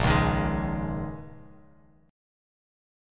Bbm11 chord